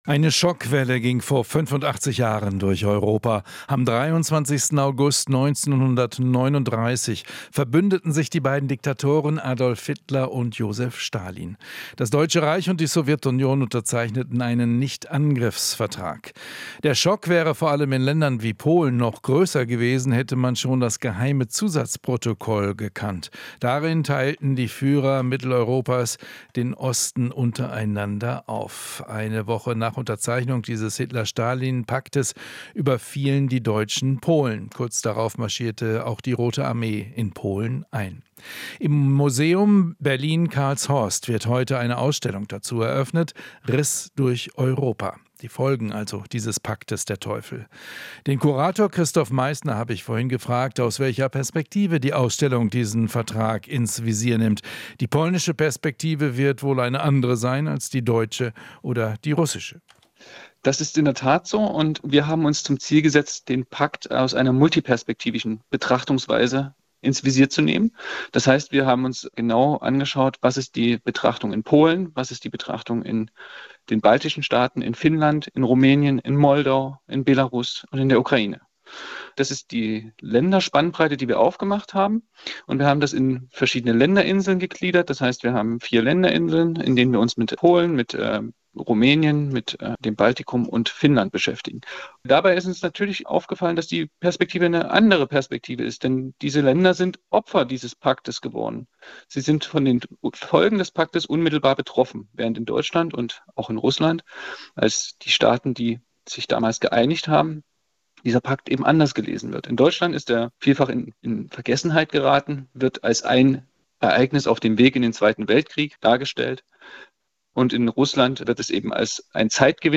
Interview - Nichtangriffspakt vor 85 Jahren: "Folgen waren dramatisch"